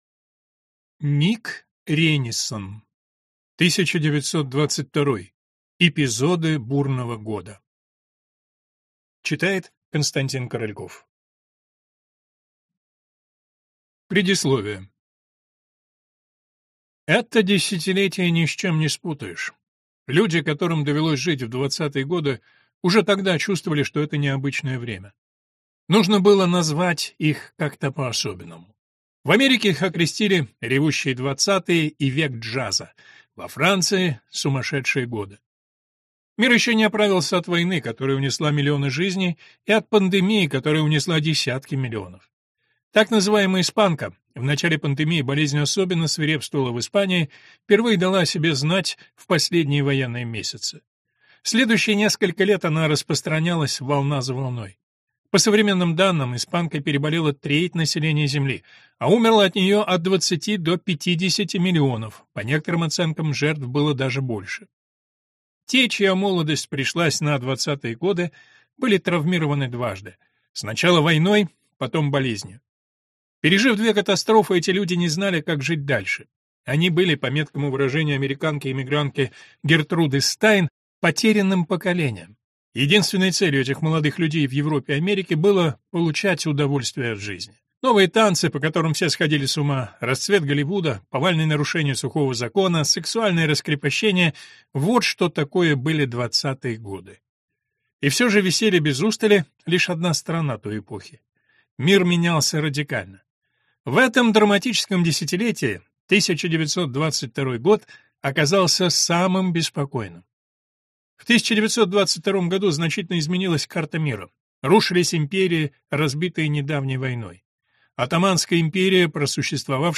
Аудиокнига 1922: Эпизоды бурного года | Библиотека аудиокниг